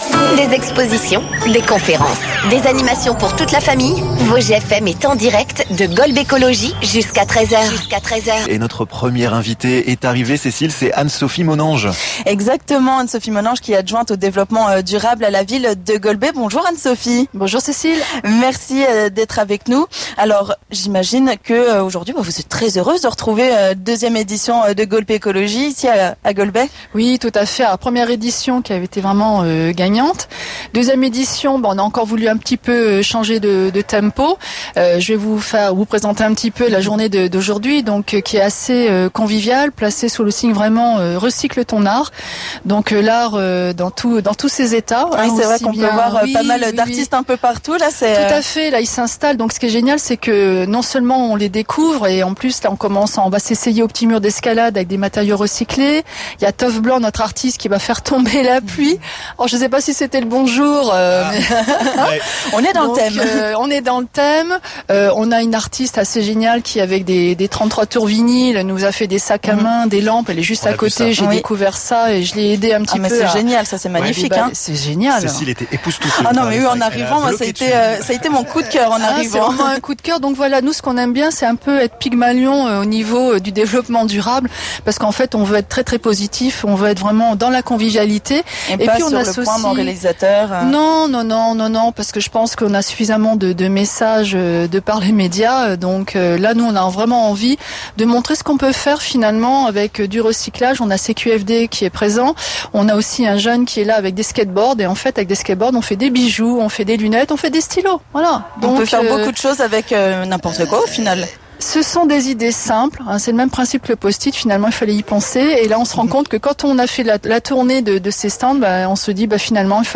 Revivez notre émission du samedi 17 septembre en direct du Festival Golbécologie à Golbey !